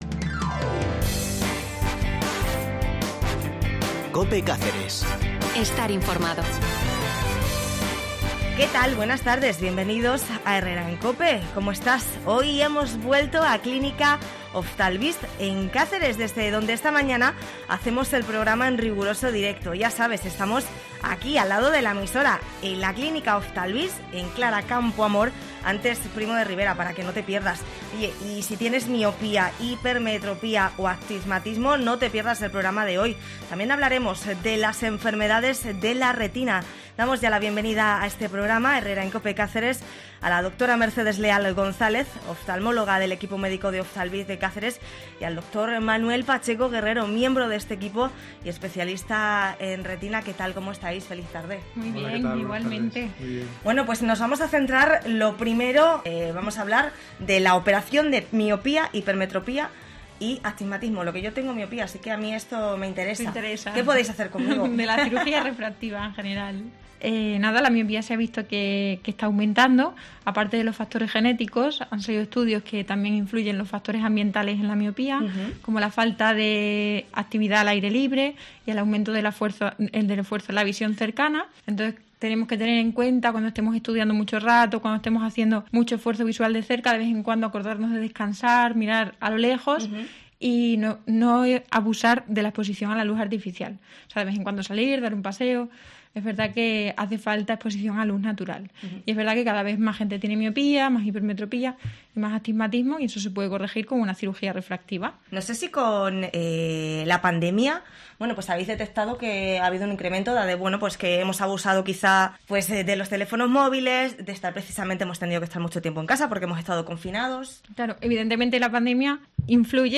AUDIO: Vuelve a escuchar Herrera en Cope Cáceres, 15 de marzo, desde la clínica Oftalvist de la capital cacereña.